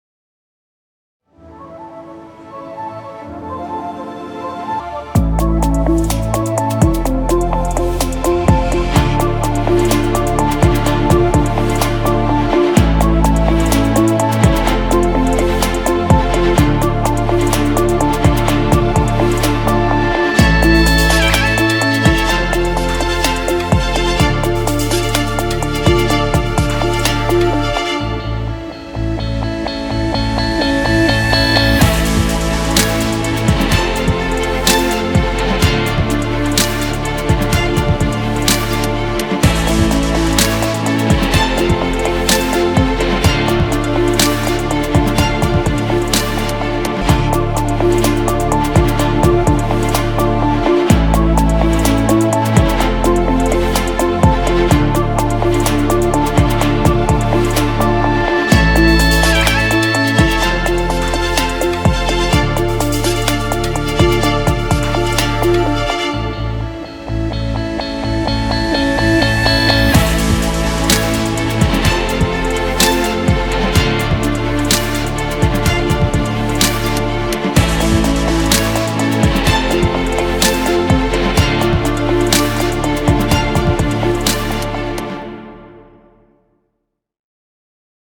tema dizi müziği, rahatlatıcı eğlenceli enerjik fon müziği.